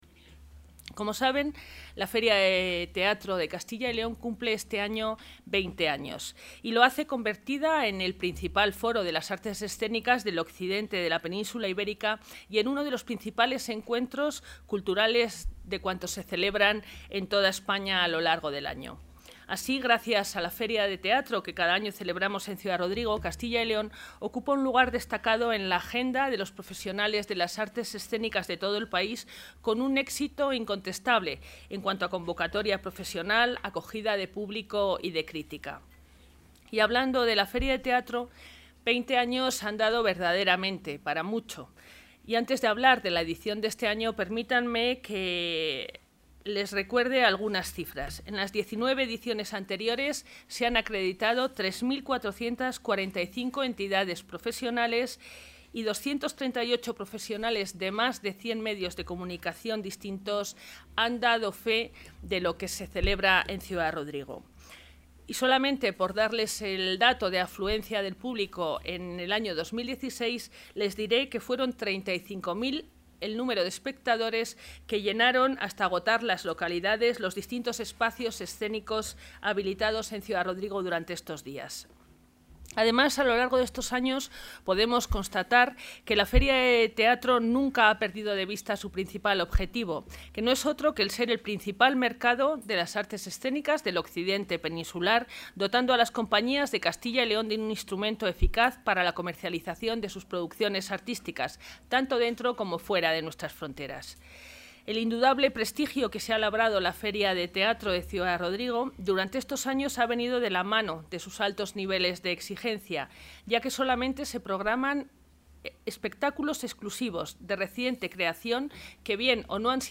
Declaraciones consejera de Cultura y Turismo.